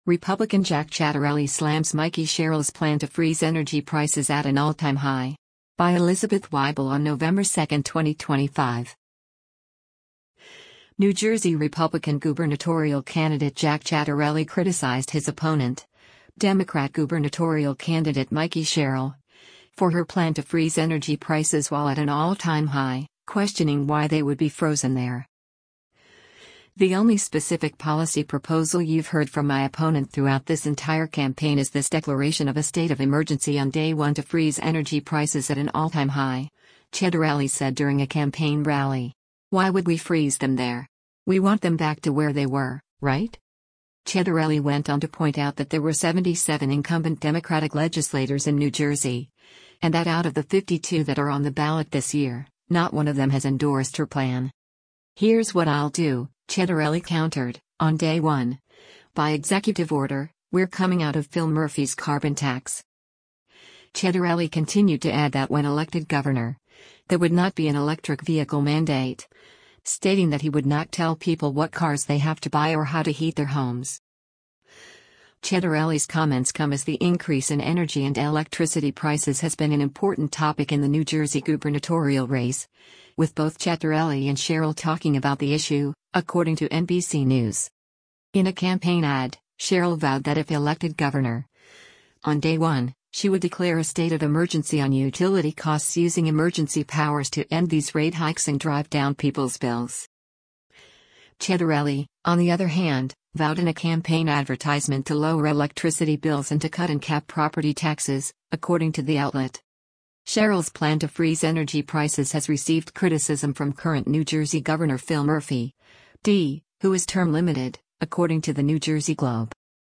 “The only specific policy proposal you’ve heard from my opponent throughout this entire campaign is this declaration of a state of emergency on day one to freeze energy prices at an all-time high,” Ciattarelli said during a campaign rally.